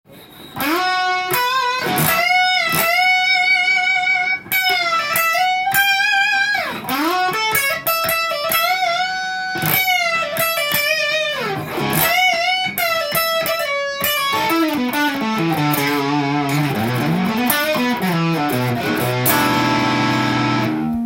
試しに弾いてみました
さすがハイポジションに特化したエレキギターです。